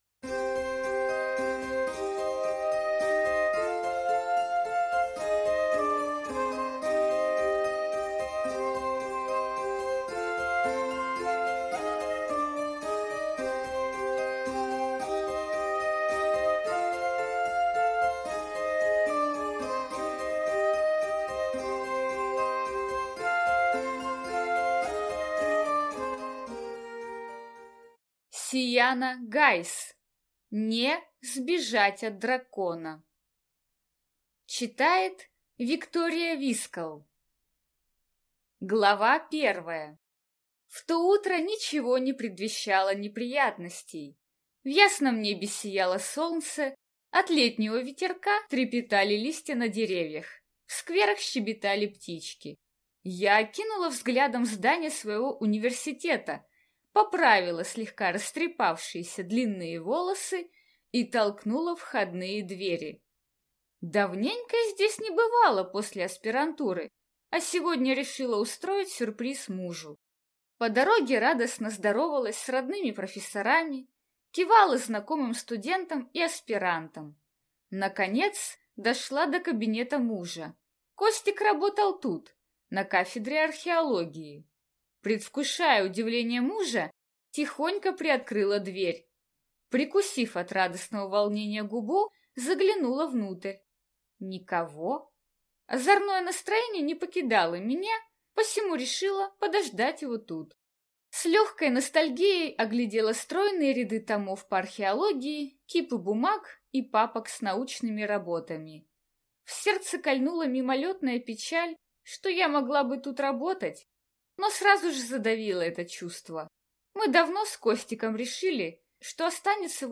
Аудиокнига (Не)Сбежать от дракона | Библиотека аудиокниг